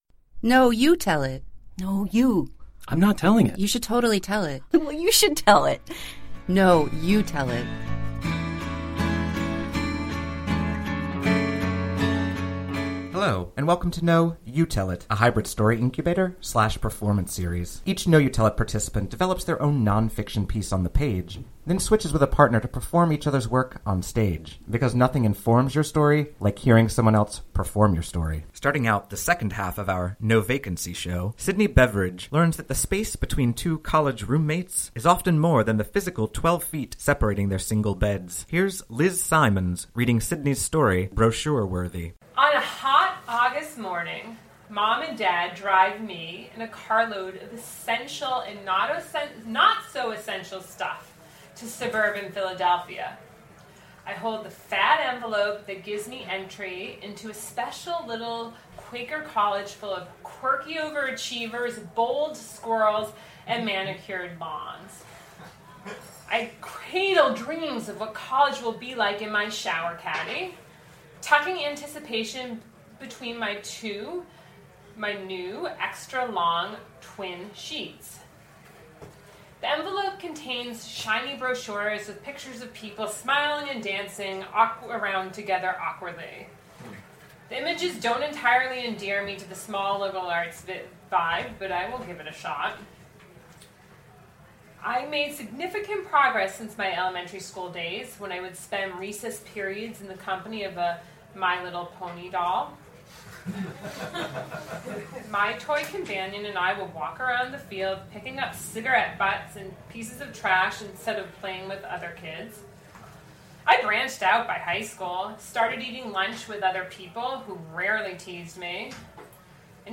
Starting out the second half of our recent “No Vacancy” show at Jimmy’s No. 43